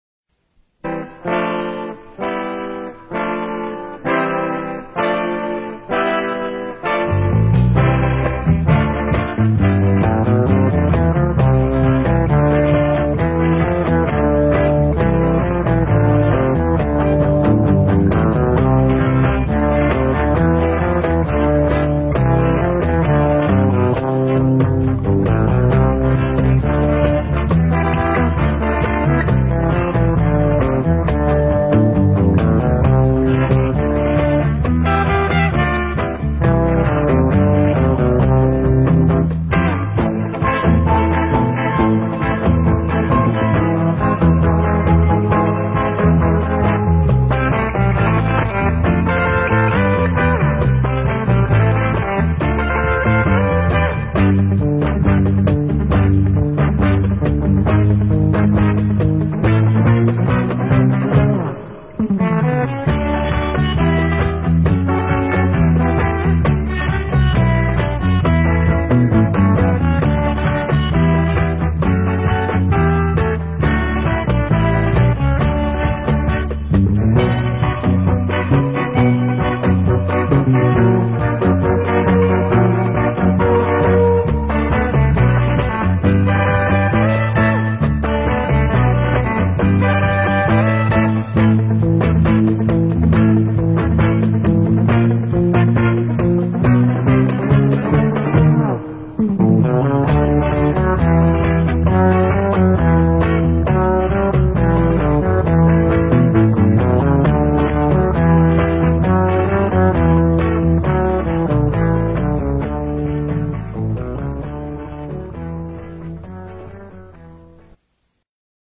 One source claims it was unreleased, but that seems unlikely; it’s possibly the work of a tribute band.